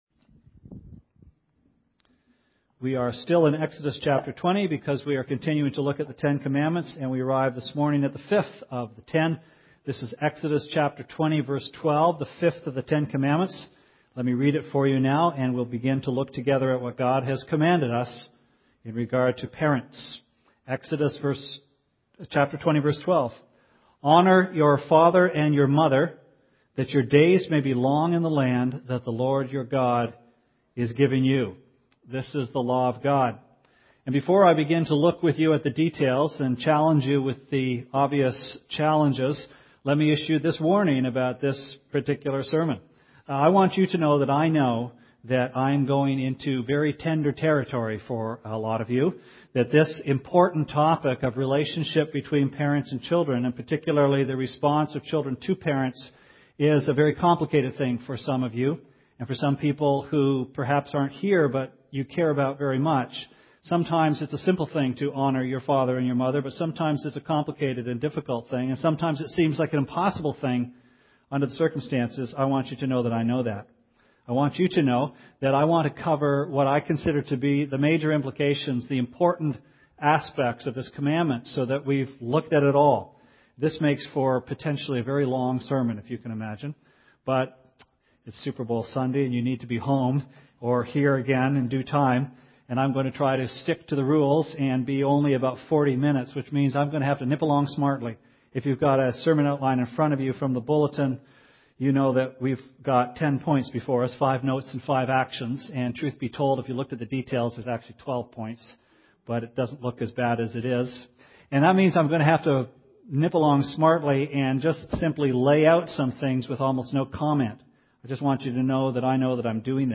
Sermon Archives - West London Alliance Church